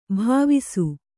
♪ bhāvisu